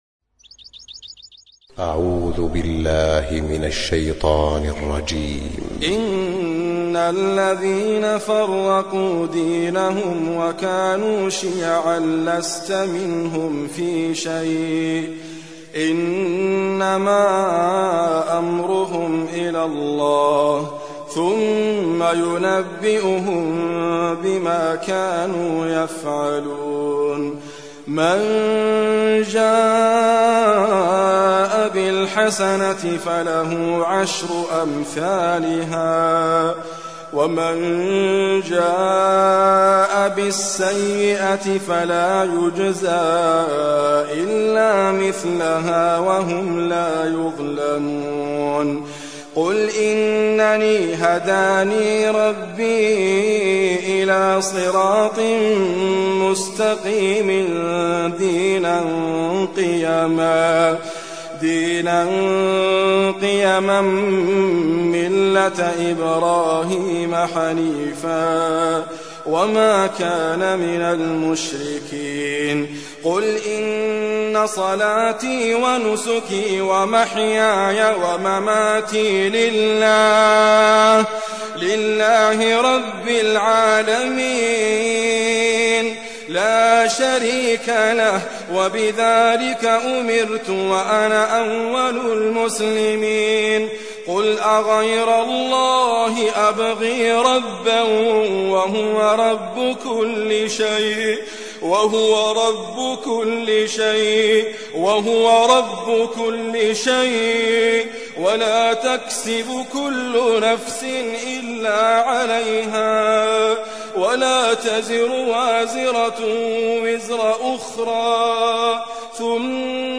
Recitime
Idriss Abkar